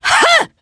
Demia-Vox_Attack1_jp.wav